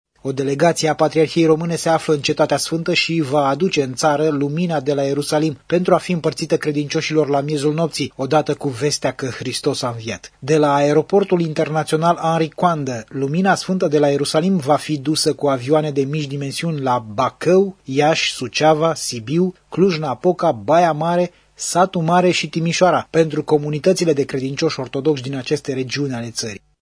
Transmite redactorul